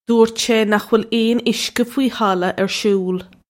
Pronunciation for how to say
doort shay nukh will ane ishka fwee halla irr shool
This is an approximate phonetic pronunciation of the phrase.
This comes straight from our Bitesize Irish online course of Bitesize lessons.